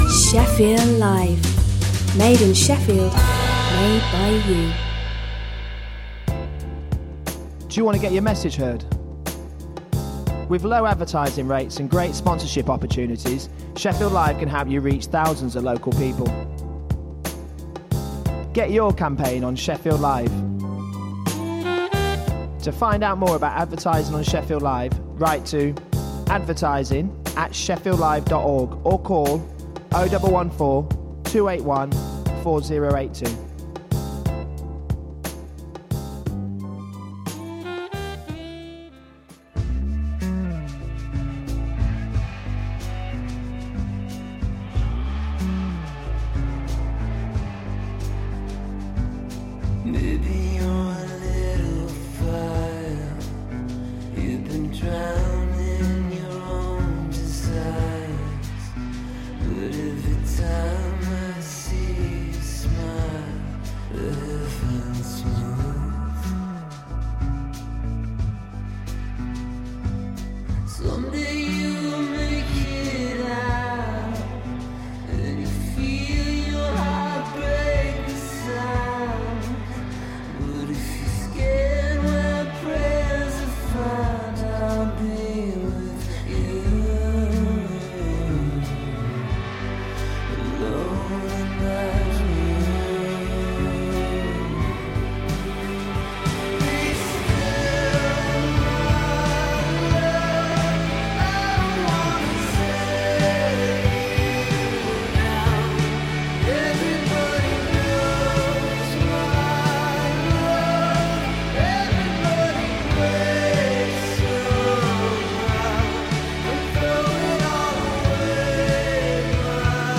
Underground, local, global, universal music and people.